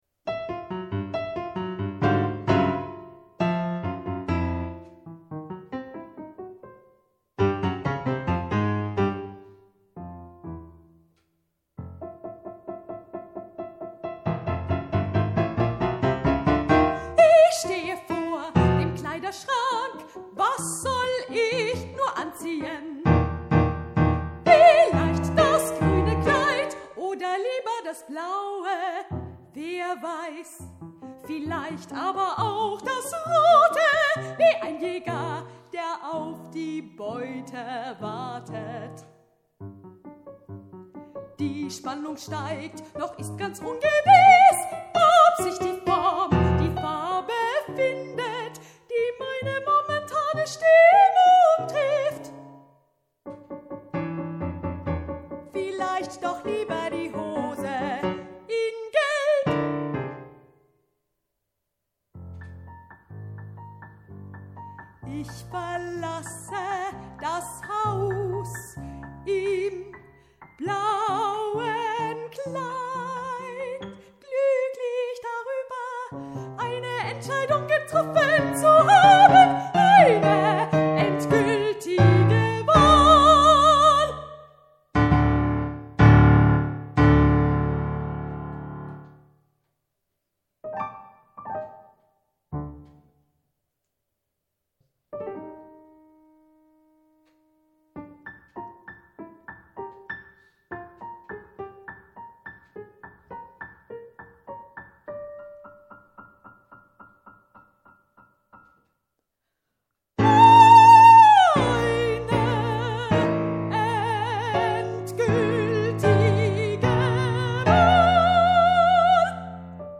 ein moderner Liederabend